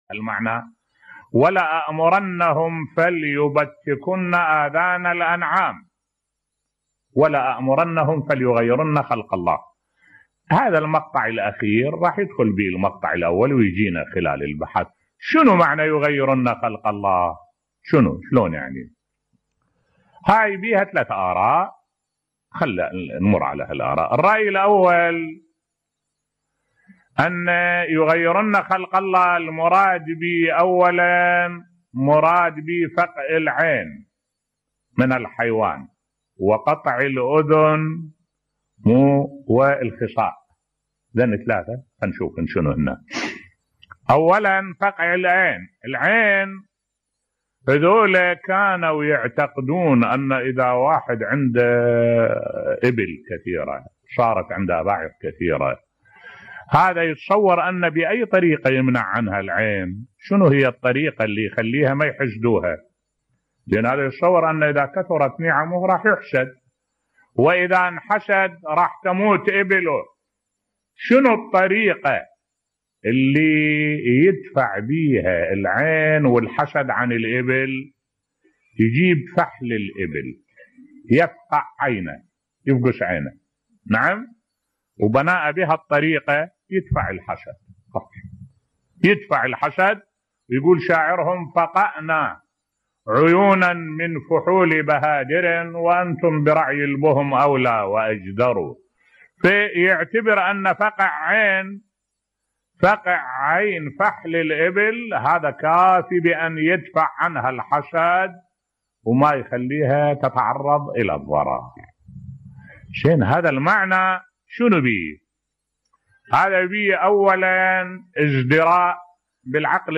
ملف صوتی تغيير عرب الجاهلية لخلق الله في حيواناتهم بصوت الشيخ الدكتور أحمد الوائلي